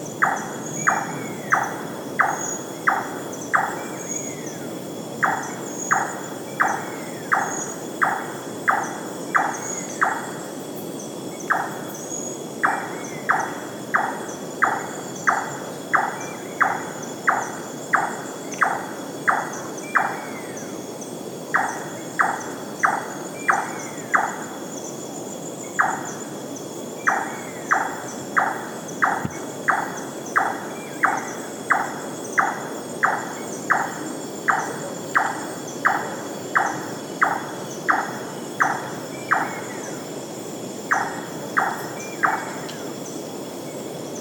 7. Тайные крики козодоя ночью